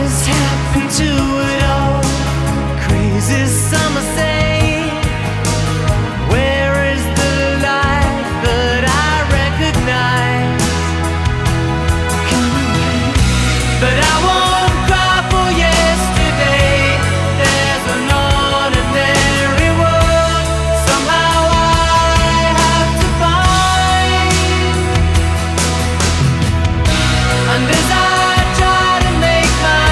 • Rock
keyboards
drums